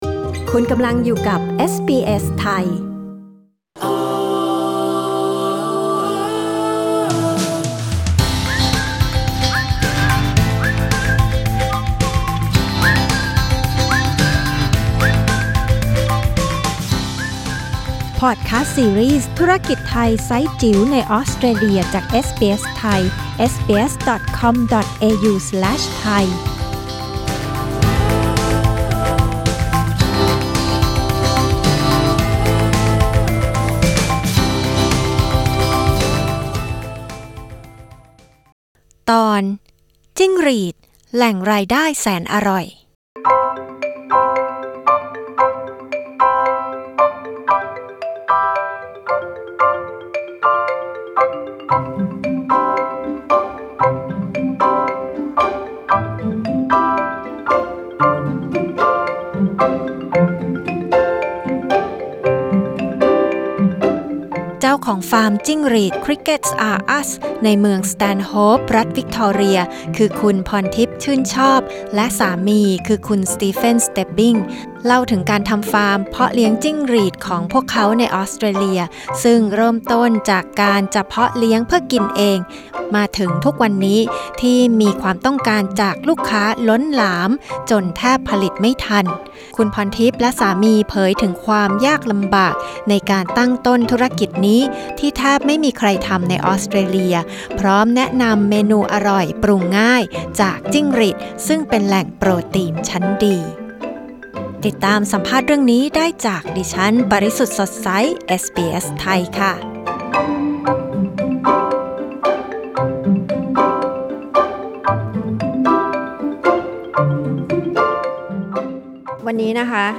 กดปุ่ม 🔊 ที่ภาพด้านบนเพื่อฟังสัมภาษณ์เรื่องนี้